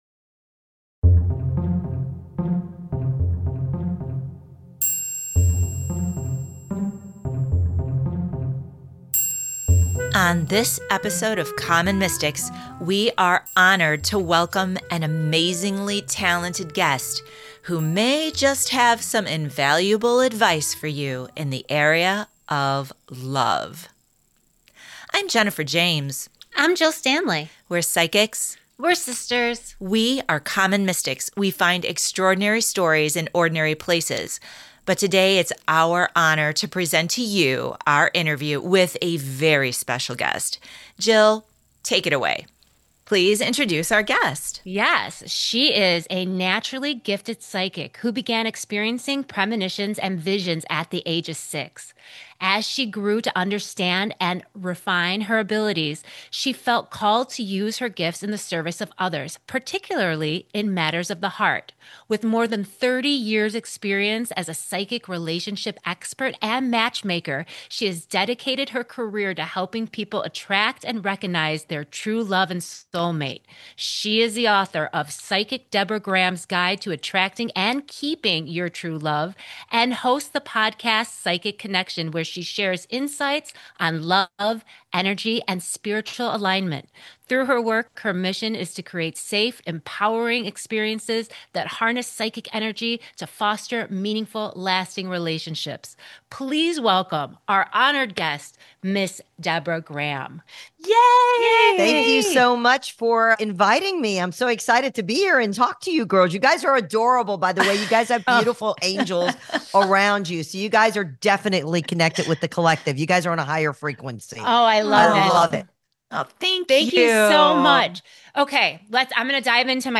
145: A Spirited Conversation